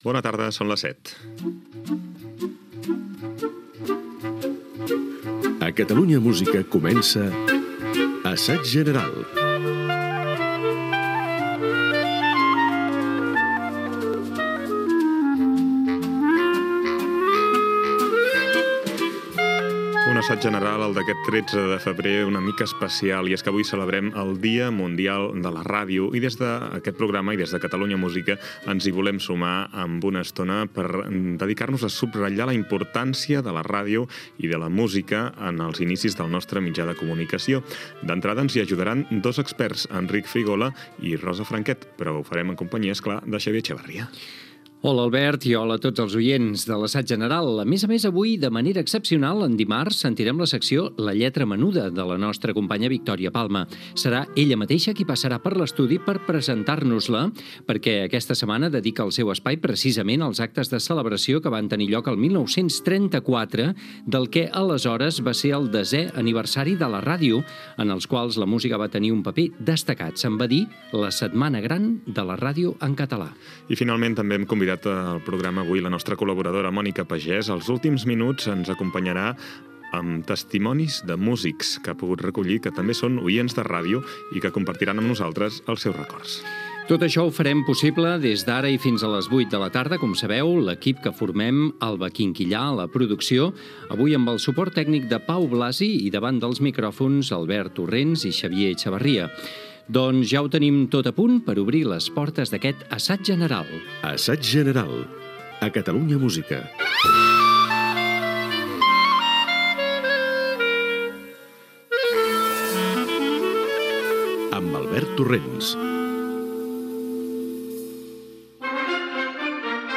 Careta del programa, presentació, sumari, equip, indicatiu, els orígens de la ràdio a Catalunya